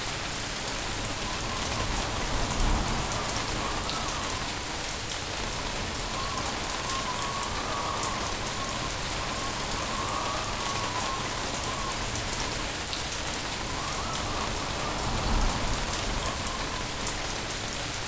Heavy Rain 1.wav